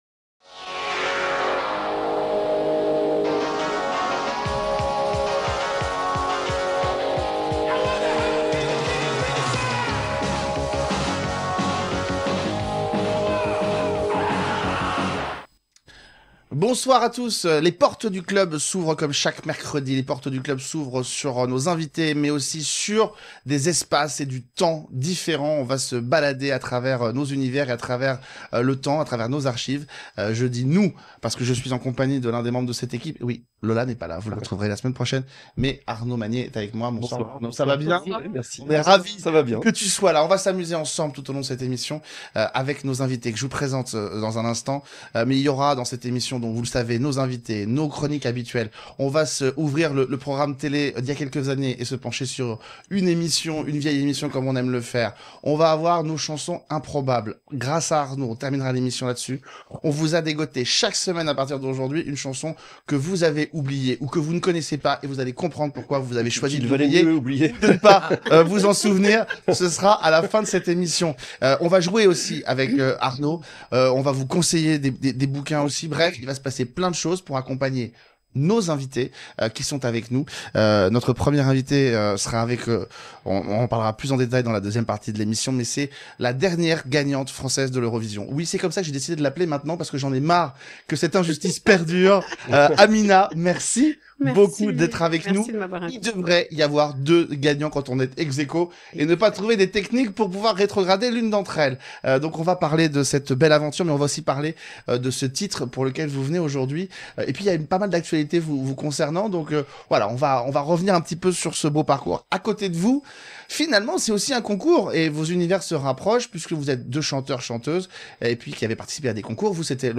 Nous recevons pour une complète réhabilitation la dernière gagnante française à l’Eurovision (on vous raconte tout dans l’émission), Amina est notre invitée dans cette émission. Elle revient sur son parcours dans la chanson et cette expérience incroyable au plus grand concours de chant du monde.